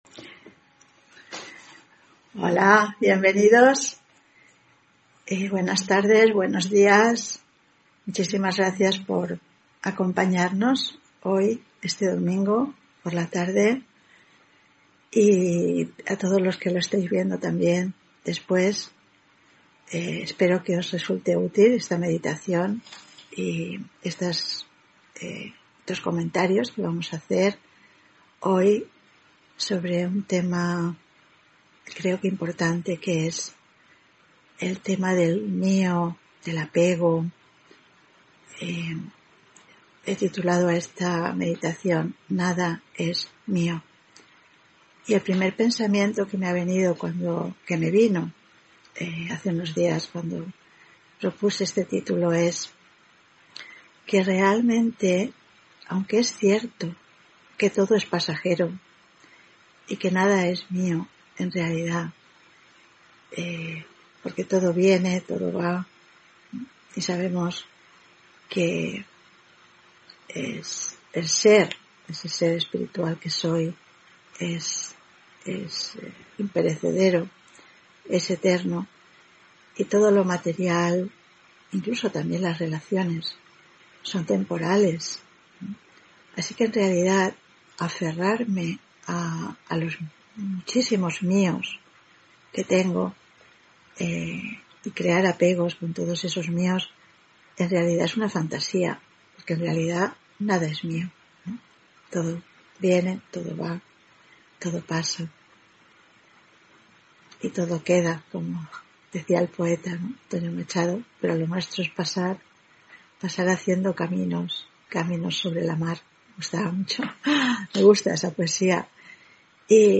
Meditación y conferencia: Nada es mío (23 Enero 2022)